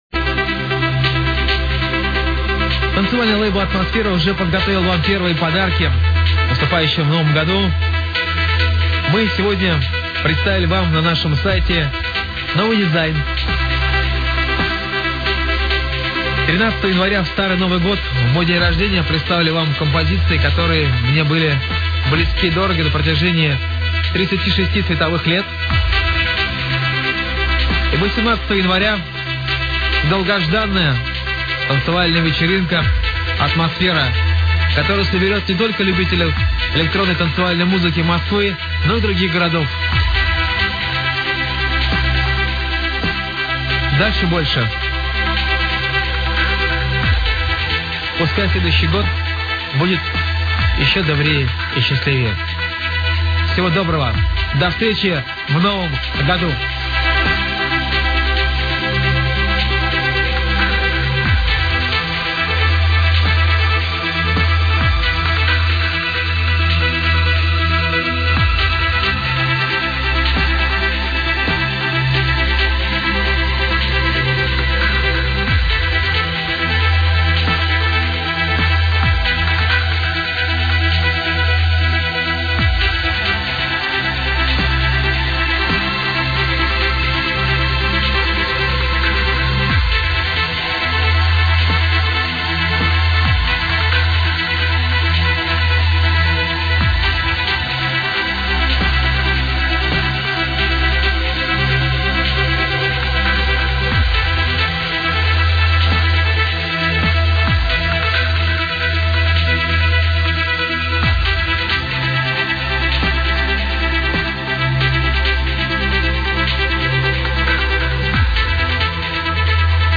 Thumbs up The GREAT chill ! beaty music, define it!!